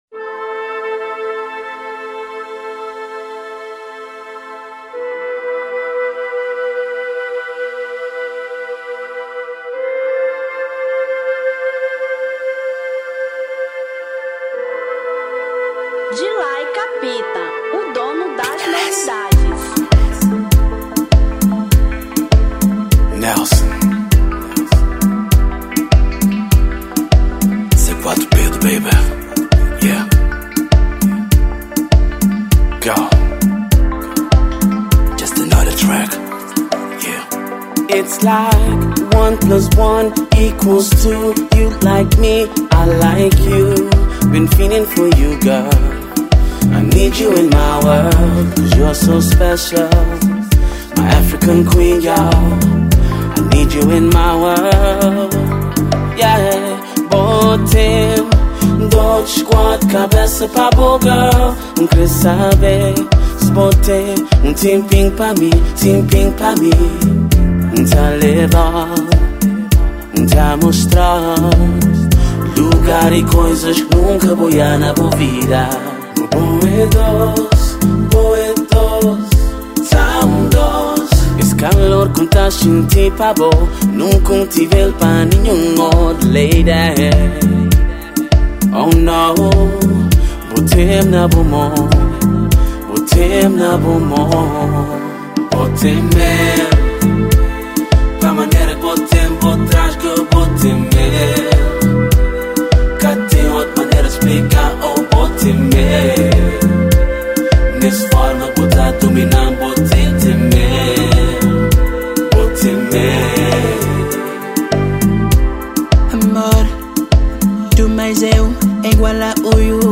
Kizomba 2013